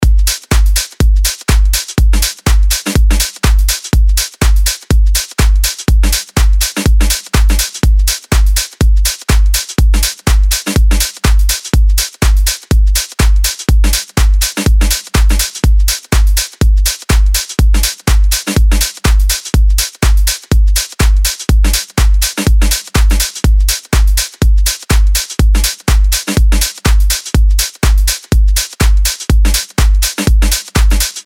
LP 127 – DRUM LOOP – EDM – 123BPM